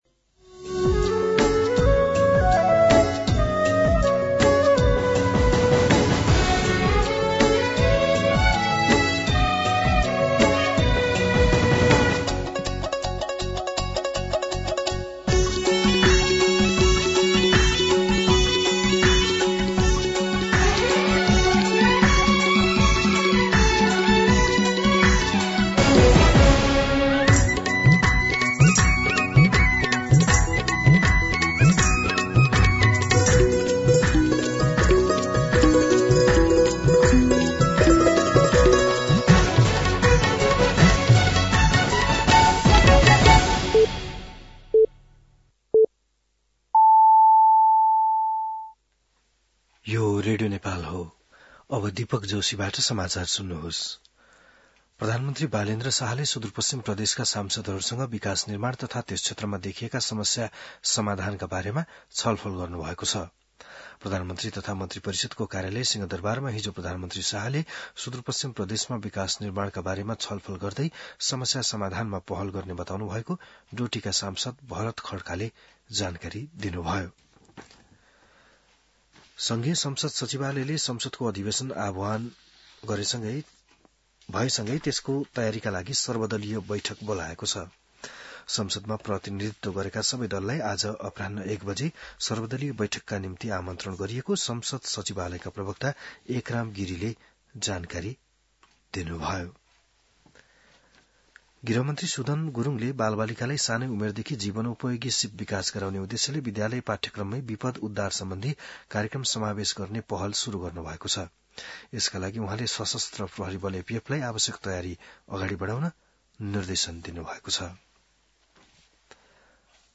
बिहान ११ बजेको नेपाली समाचार : १८ चैत , २०८२